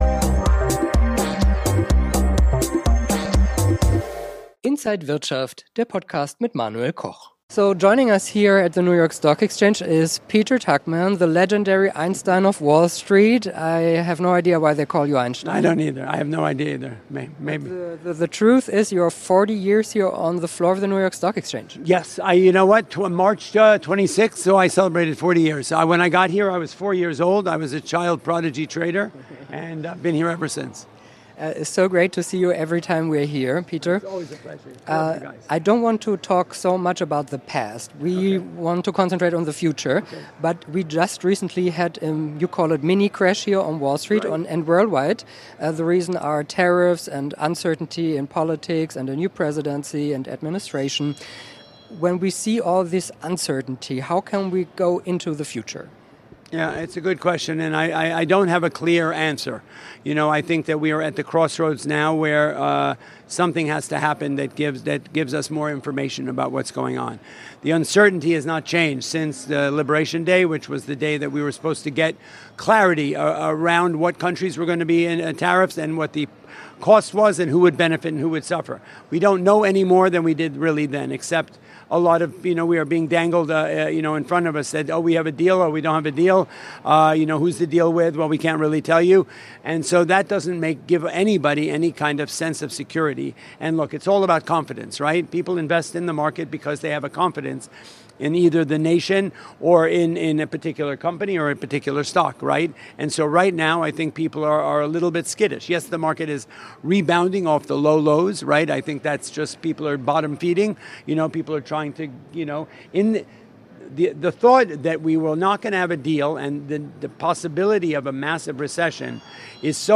Interview
an der New York Stock Exchange